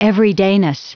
Prononciation du mot everydayness en anglais (fichier audio)
Prononciation du mot : everydayness